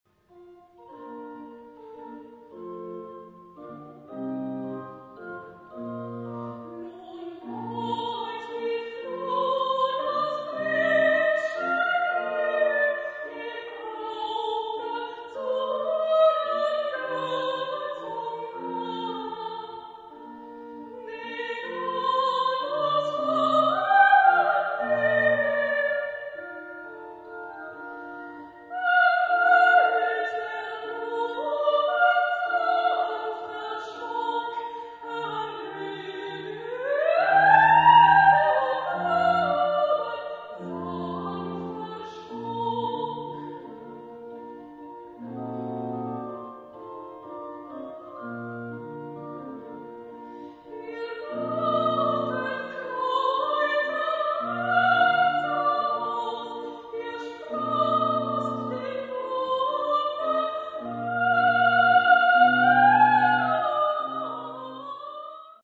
Konzert in Altenberg am 29. März 2009
Klangprobe aus klassischer Zeit